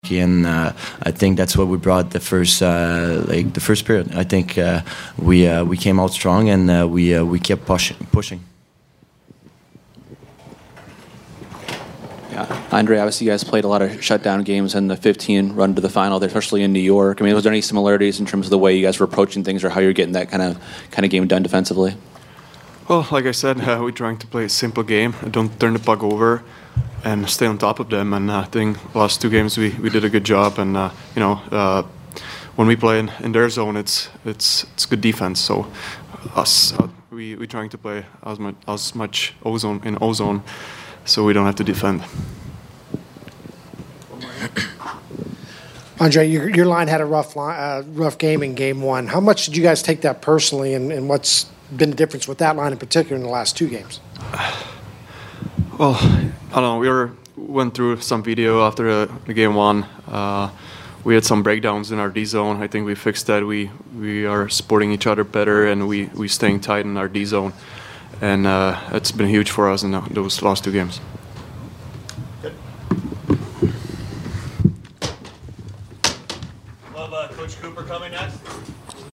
Palat/Gourde Post-Game 5/2 @ BOS